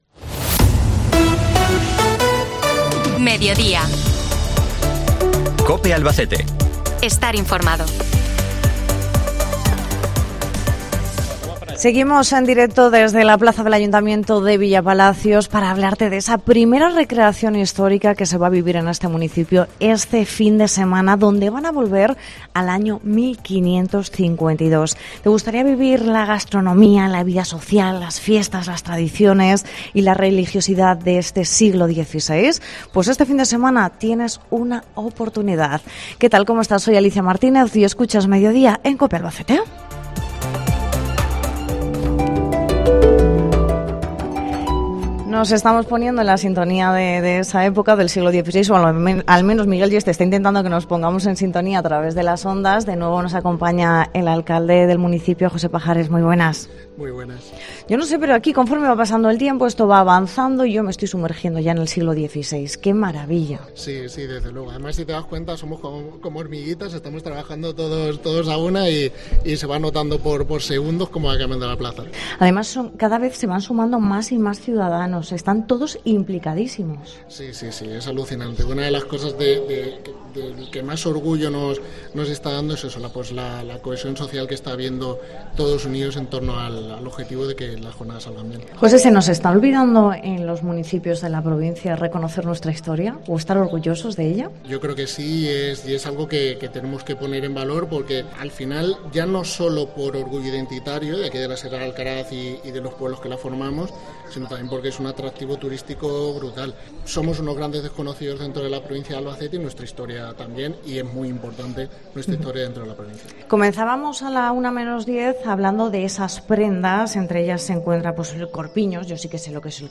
Cope Albacete ha viajado hasta Villapalacios para vivir en in situ los momentos previos a este gran acontecimiento.